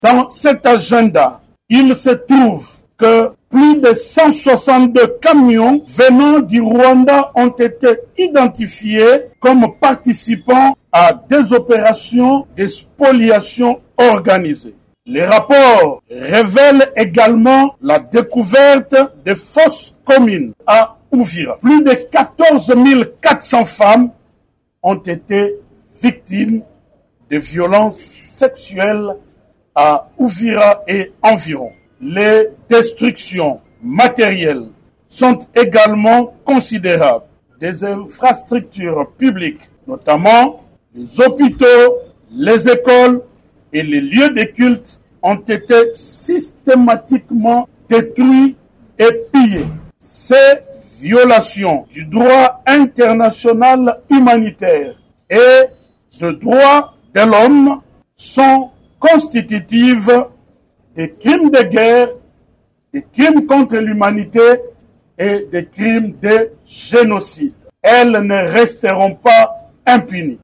Ces chiffres sont contenus dans un rapport du Gouvernement, présenté mardi à Kinshasa devant la presse par le ministre des Droits humains, Samuel Mbemba.
Extrait de l’allocution du ministre des Droits humains: